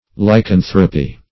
Lycanthropy \Ly*can"thro*py\, n. [Gr. ?: cf. F. lycanthropie.]